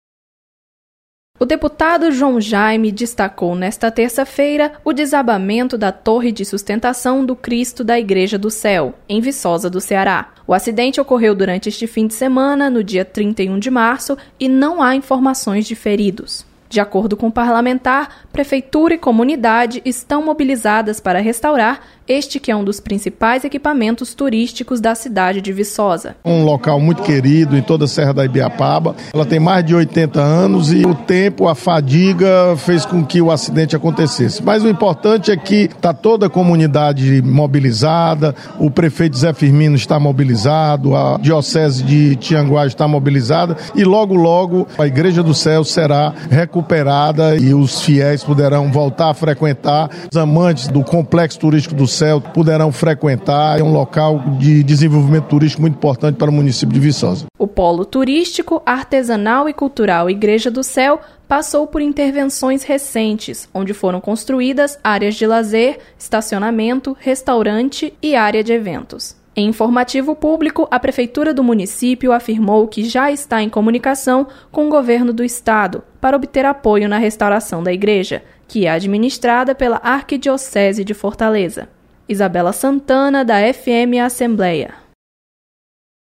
Deputado comenta desabamento de torre de igreja em Viçosa do Ceará.